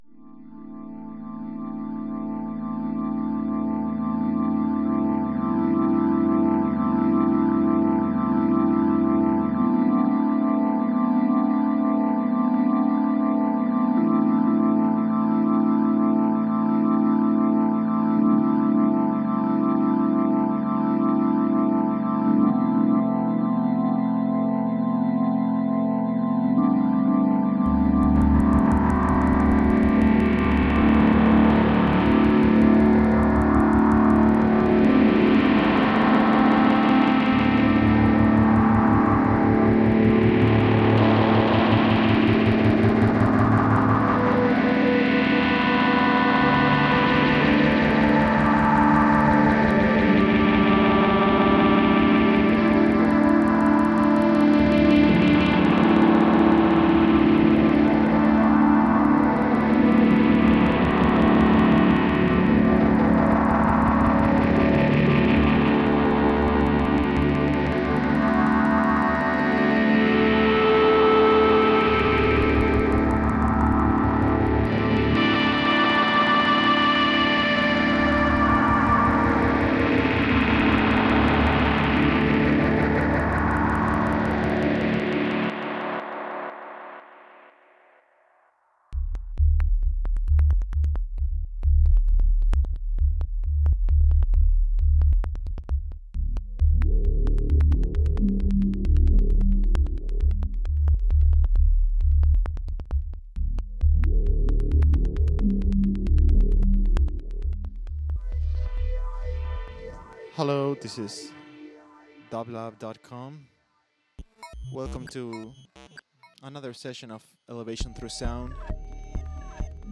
Alternative Avant-Garde Electronic Folk Rock